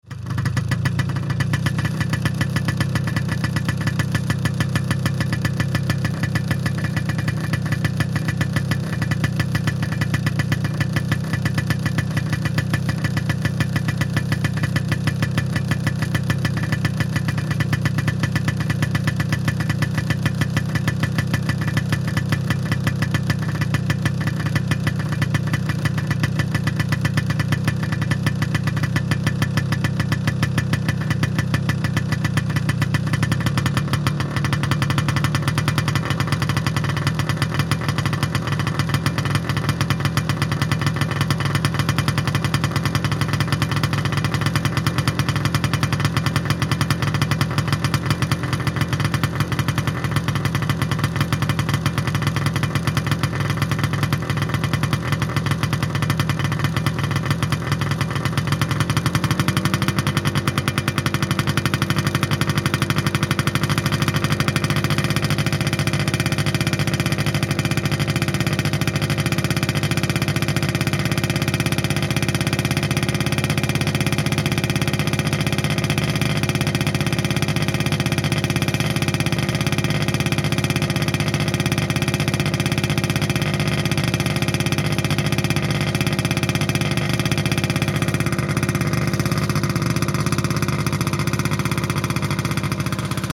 На этой странице собраны звуки снегохода: рев мотора, скрип снега под гусеницами, свист ветра на скорости.
Звук снегохода Yamaha на холостом ходу